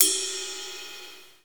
normal-hitwhistle.mp3